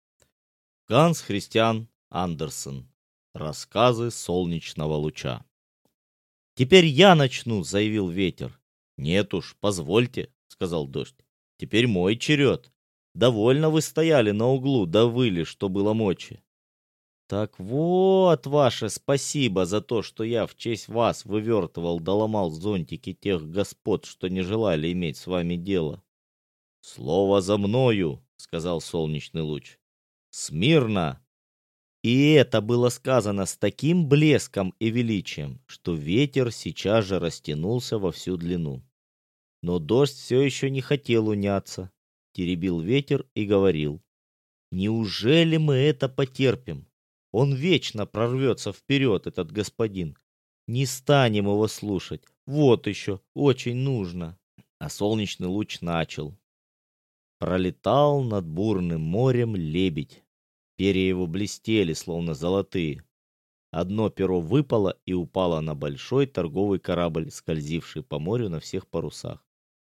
Аудиокнига Рассказы солнечного луча | Библиотека аудиокниг